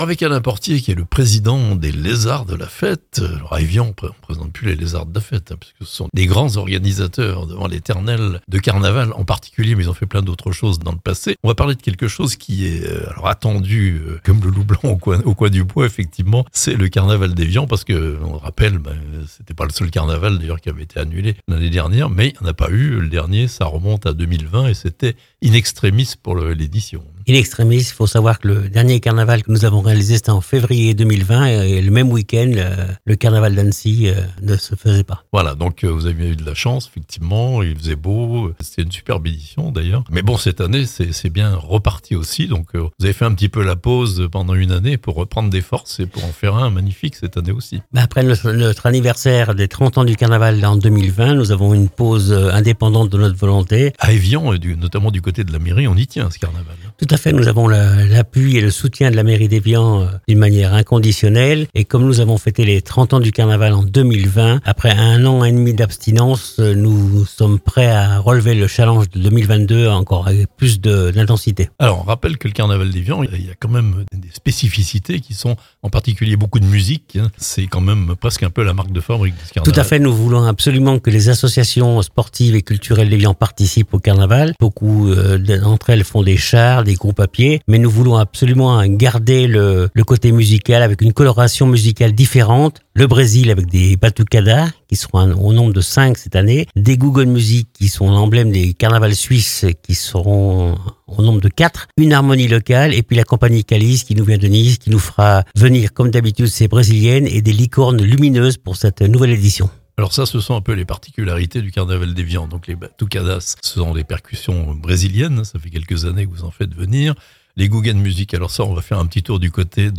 Le retour du carnaval à Evian (interview)